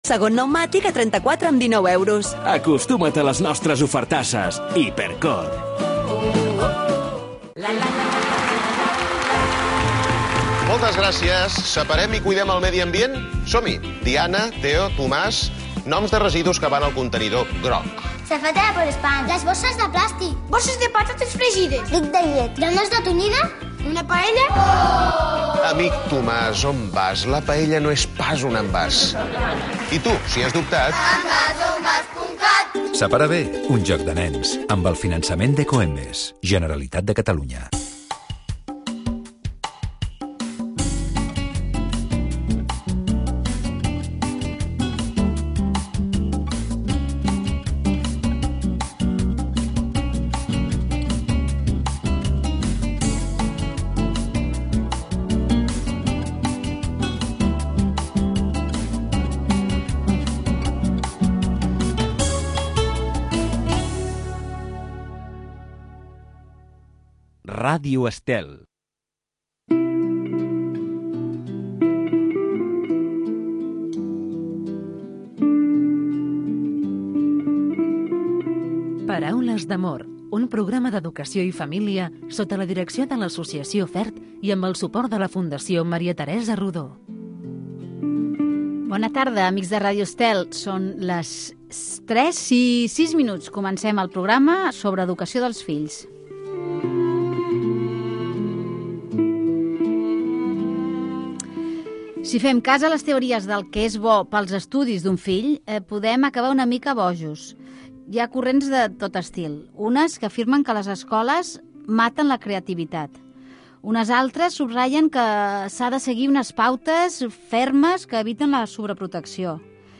Un programa amb entrevistes i tertúlia sobre la família amb clau de valors humans, produït pel l'associació FERT.